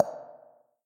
描述：水滴落在高混响的地方。
标签： 下水道
声道立体声